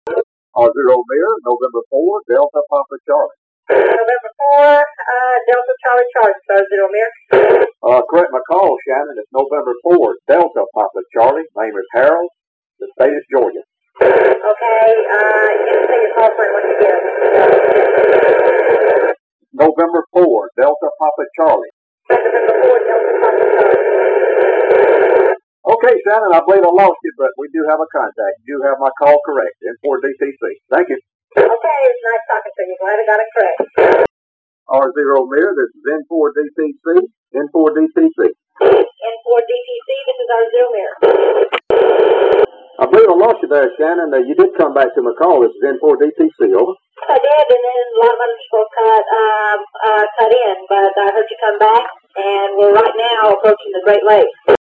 These recordings were converted to files with a nice program that filters much of the noise and allows editing to cut out undesirable areas of noise and silence. I have two contacts with Dr. Lucid on 19/May/96 and 20/May/96.